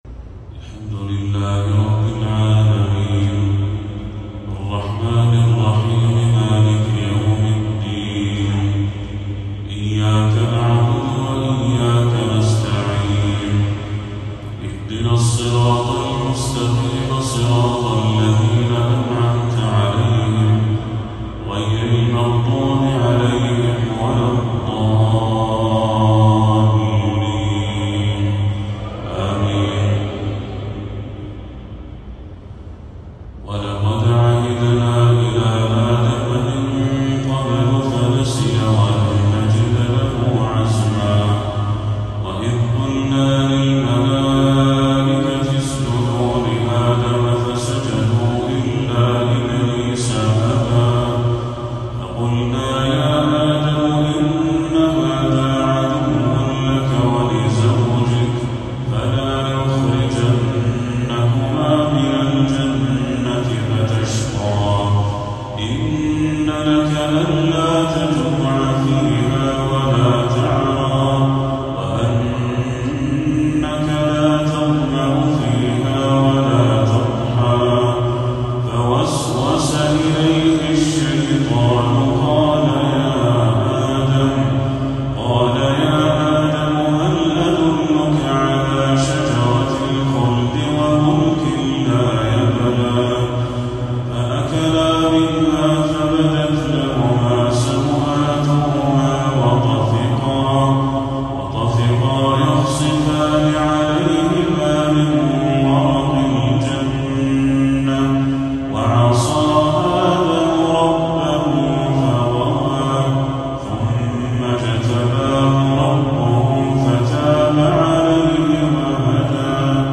تلاوة بديعة لخواتيم سورة طه للشيخ بدر التركي | عشاء 11 ربيع الأول 1446هـ > 1446هـ > تلاوات الشيخ بدر التركي > المزيد - تلاوات الحرمين